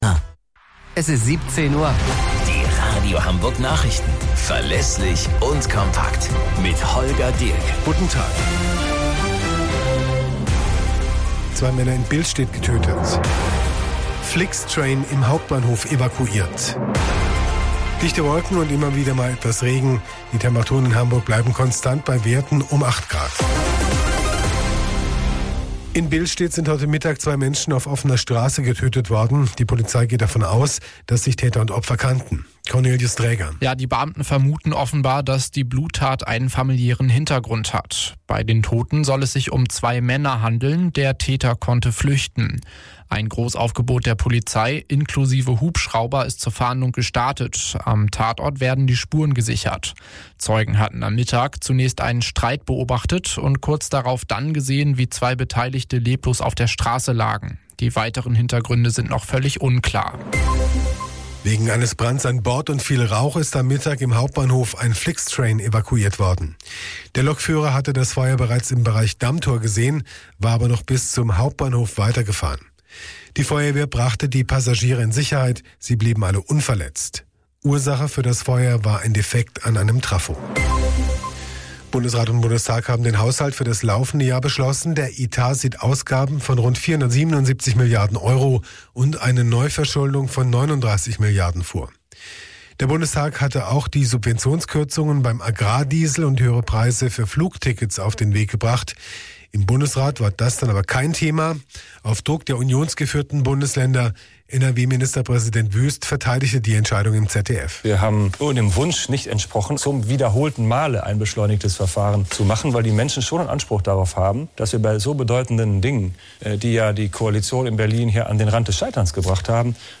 Radio Hamburg Nachrichten vom 06.04.2024 um 01 Uhr - 06.04.2024